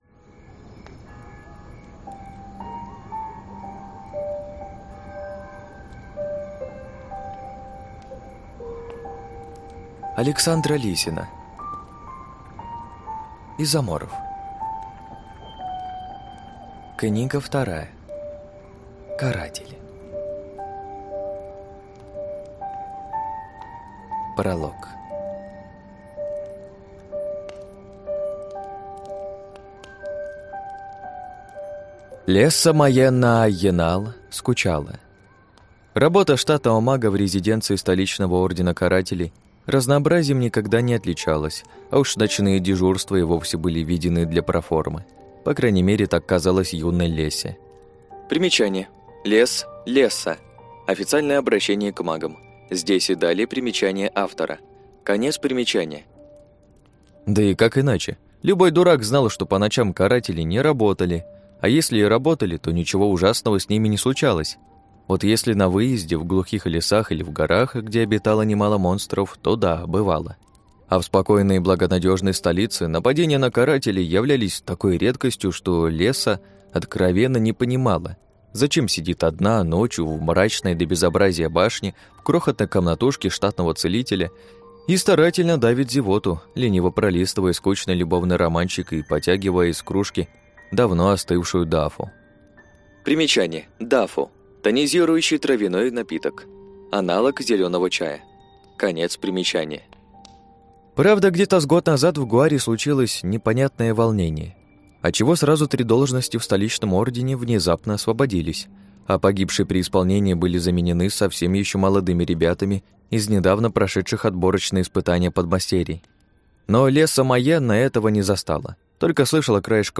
Аудиокнига Изоморф. Каратель | Библиотека аудиокниг